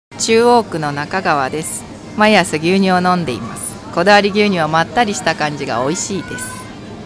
試飲いただいたお客様の生の声
リンクをクリックするとこだわり牛乳を試飲いただいた皆様からの感想を聞くことができます。
4月1日（火）15:00～18:00　スーパーアークス 菊水店
お客様の声3